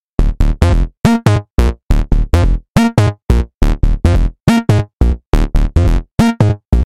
电子低音炮1
描述：A1合成器，嗡嗡声预设，切断了。
Tag: 140 bpm Electro Loops Bass Loops 1.15 MB wav Key : Unknown